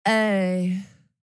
Girl Crush K-Pop Vocals by VOX
VOX_GCK_vocal_oneshot_imapact_dry_aye_laid_back_F#m
eyy-girl-crush.mp3